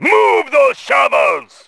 RA2-奴隶矿场-采矿b.wav